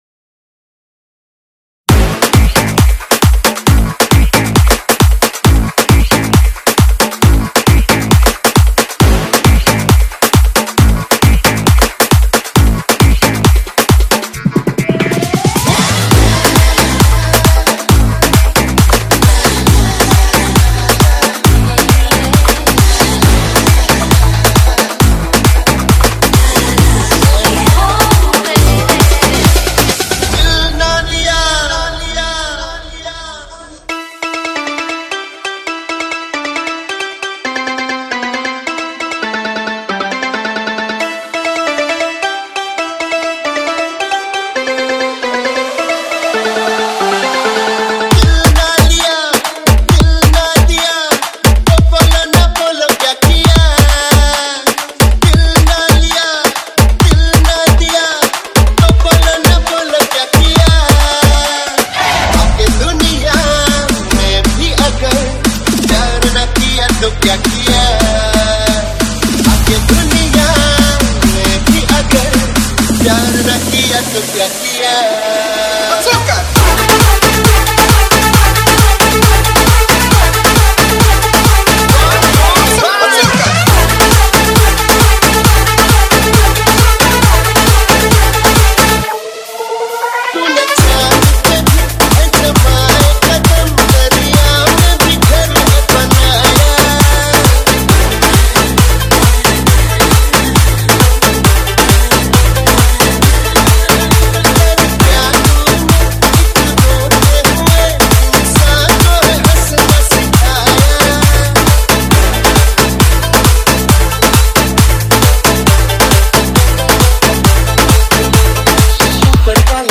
Category : Hindi Remix Song